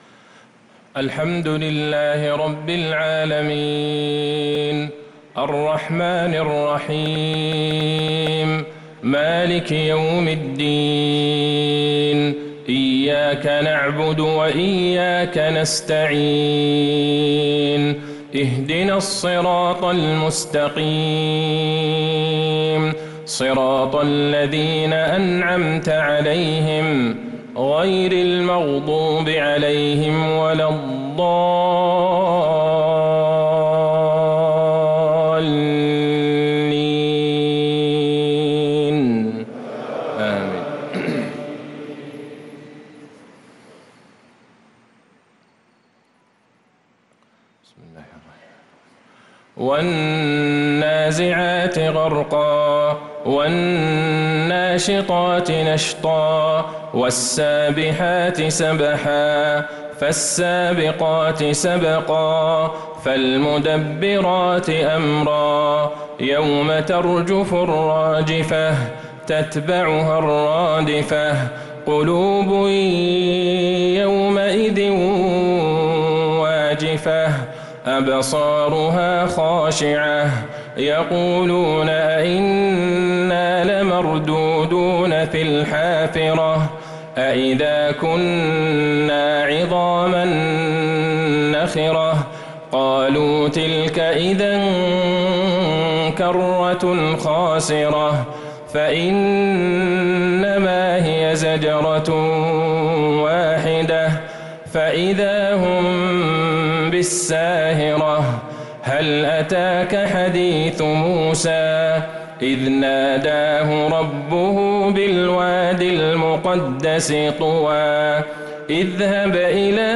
عشاء الأربعاء 1-3-1446هـ سورة النازعات كاملة | Isha prayer from Surat An-Nazi'at 4-9-2024 > 1446 🕌 > الفروض - تلاوات الحرمين